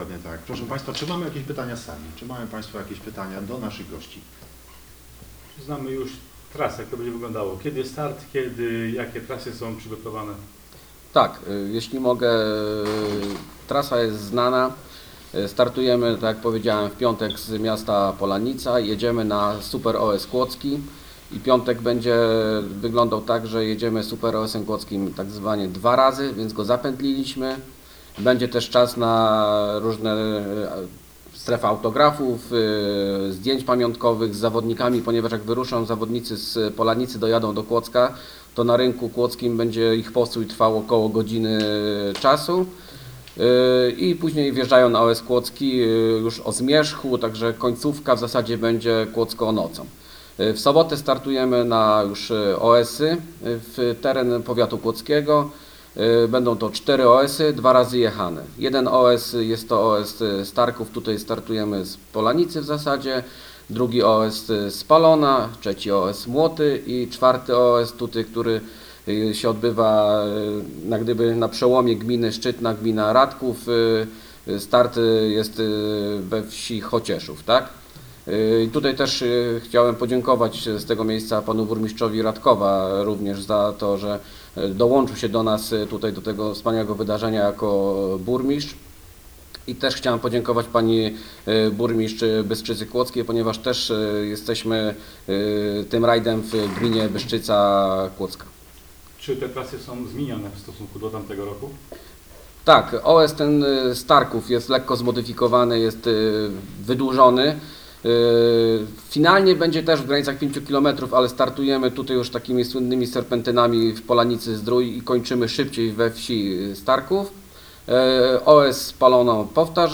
16 czerwca br. w hotelu Polanica Resort SPA odbyła się konferencja prasowa dotycząca 2. Polskiego Rajdu Legend, który rozgrywany będzie 29 i 30 sierpnia na terenie sześciu gmin powiatu kłodzkiego.